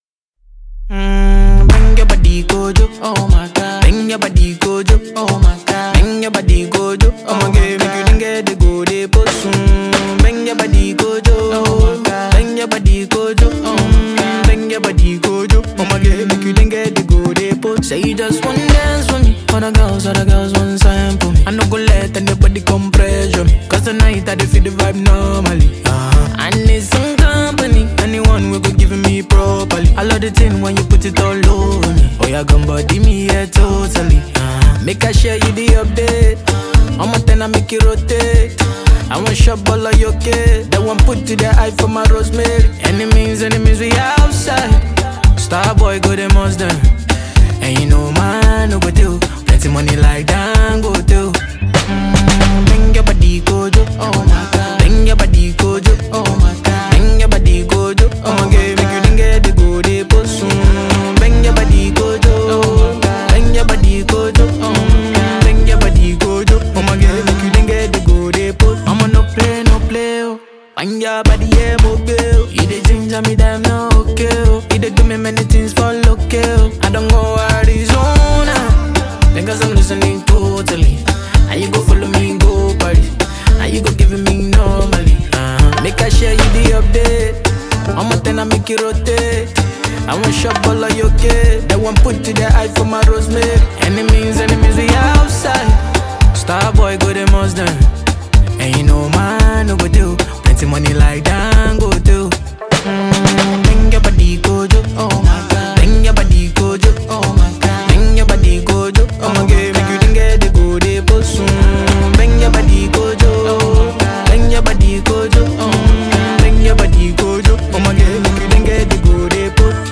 Grammy Award winning Nigerian heavyweight Afrobeat Singer